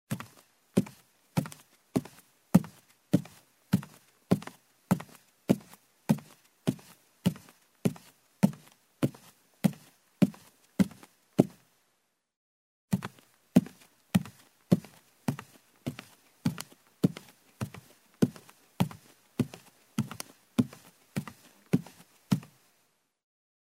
Звуки шагов по лестнице
На этой странице собраны разнообразные звуки шагов по лестнице: от легких шагов на деревянных ступенях до тяжелых шагов по бетону.